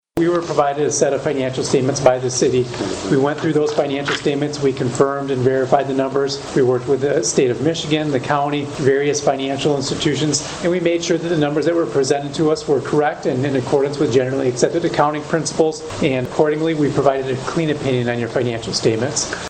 The annual audit presentation was given to the Sturgis City Commission during their meeting last week.